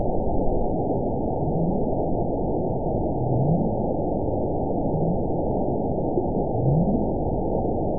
event 919759 date 01/22/24 time 13:57:53 GMT (1 year, 3 months ago) score 7.94 location TSS-AB05 detected by nrw target species NRW annotations +NRW Spectrogram: Frequency (kHz) vs. Time (s) audio not available .wav